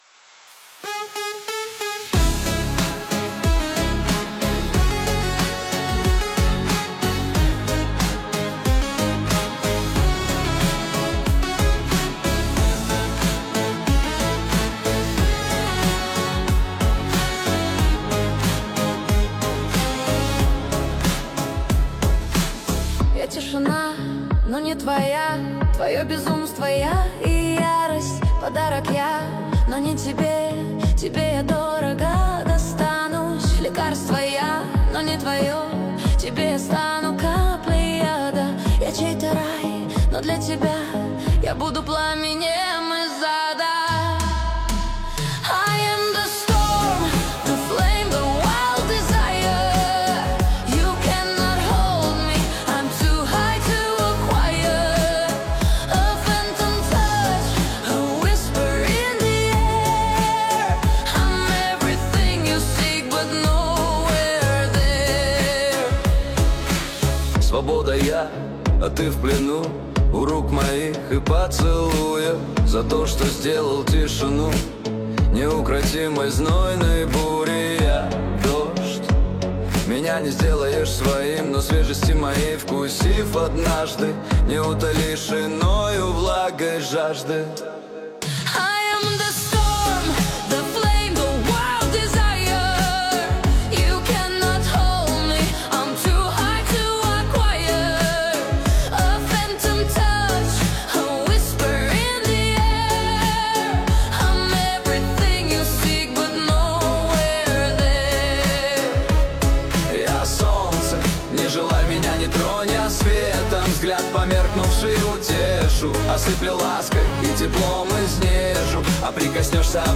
Русские и украинские песни